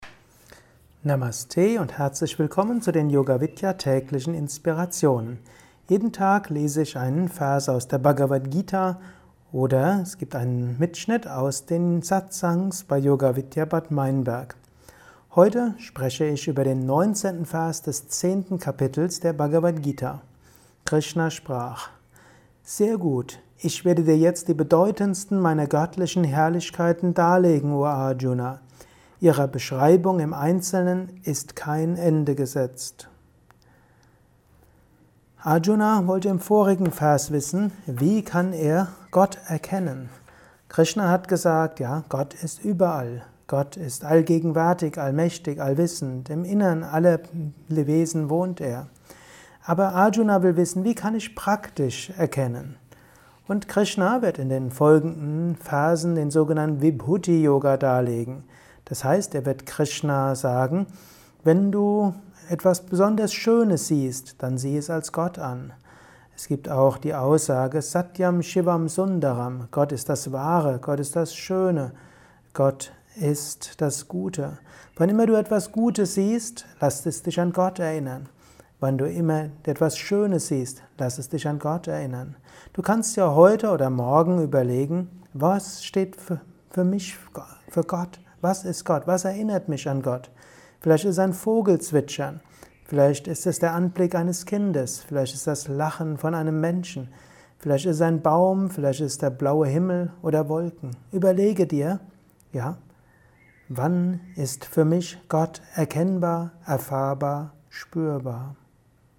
Höre einen kurzen Beitrag zur Bhagavad Gita Kapitel X. Vers 19: Beschreibung der Herrlichkeit Gottes. Gott ist das Wahre, Schöne und Gute. Dies ist ein kurzer Kommentar als Inspiration für den heutigen Tag